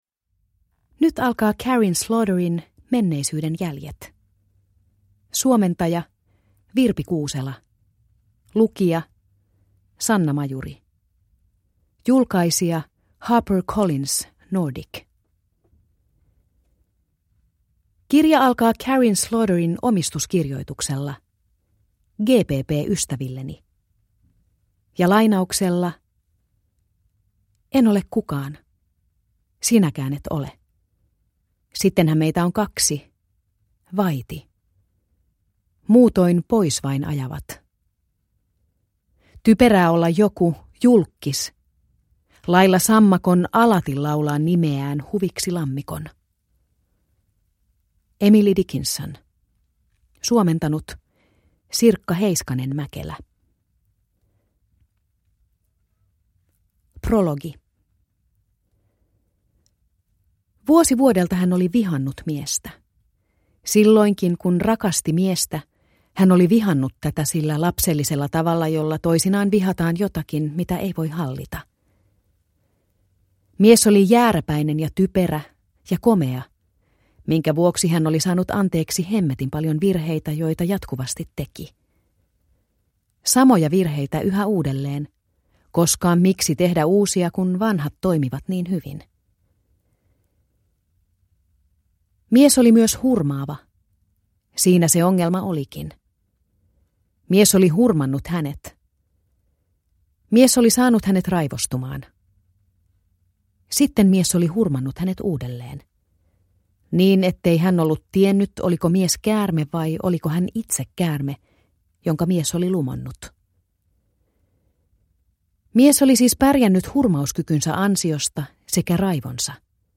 Menneisyyden jäljet – Ljudbok – Laddas ner